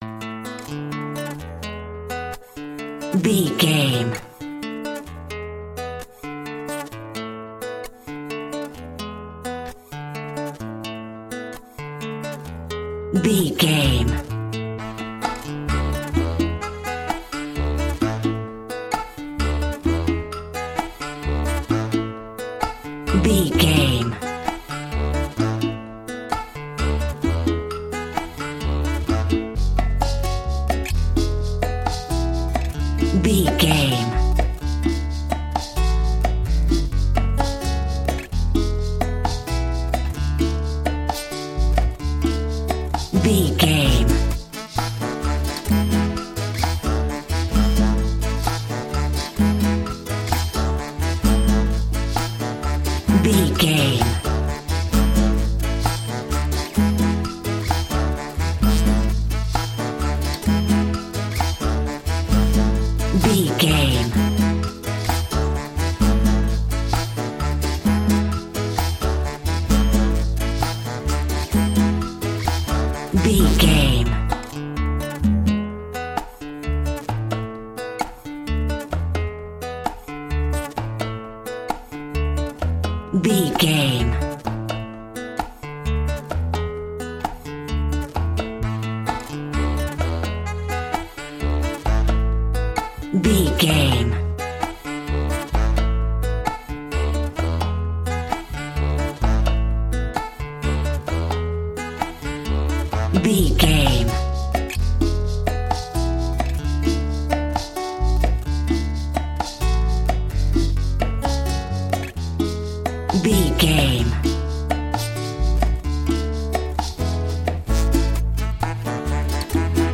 An exotic and colorful piece of Espanic and Latin music.
Ionian/Major
WHAT’S THE TEMPO OF THE CLIP?
maracas
percussion spanish guitar
latin guitar